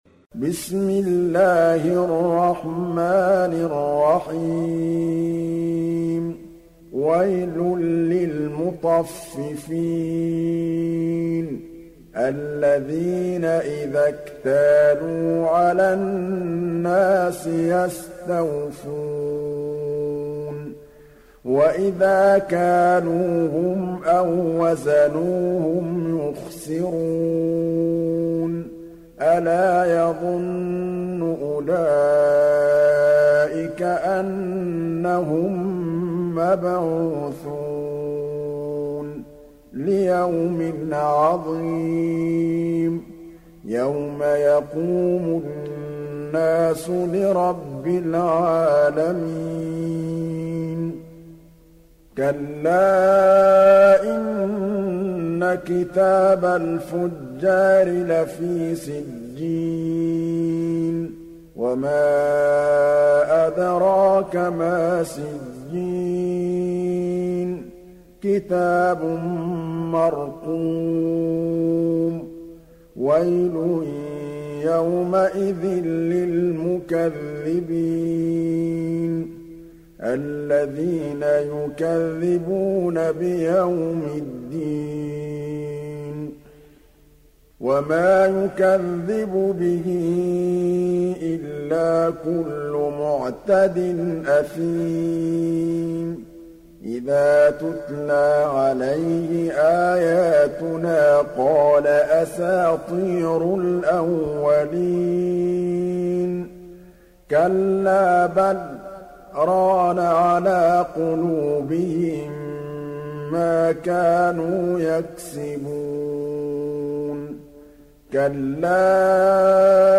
تحميل سورة المطففين mp3 بصوت محمد محمود الطبلاوي برواية حفص عن عاصم, تحميل استماع القرآن الكريم على الجوال mp3 كاملا بروابط مباشرة وسريعة